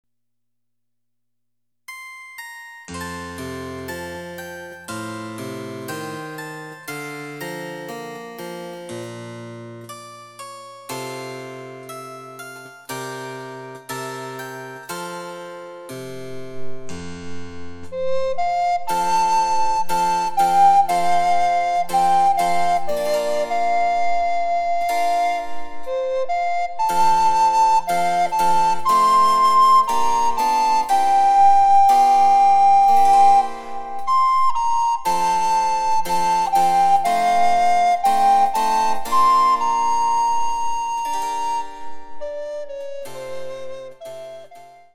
チェンバロ伴奏で楽しむ日本のオールディーズ、第５弾！
※伴奏はモダンピッチのみ。